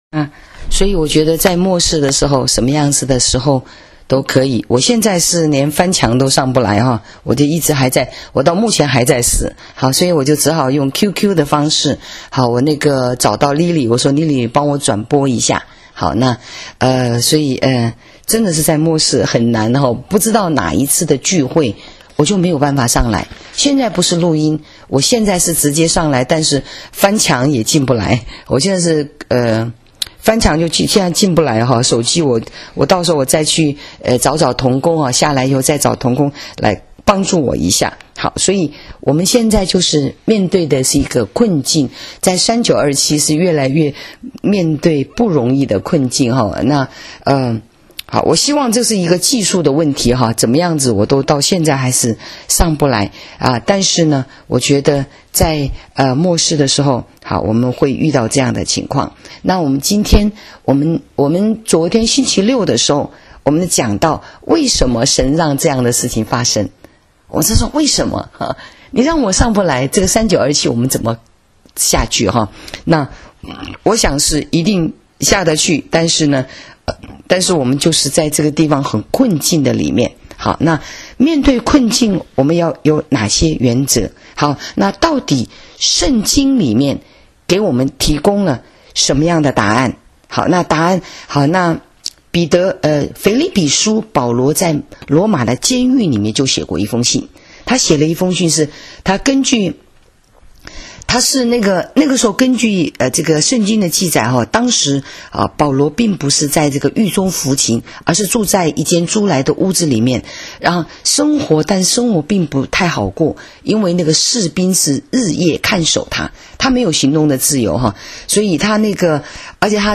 【主日信息】困境中的回应原则 （8-11-19）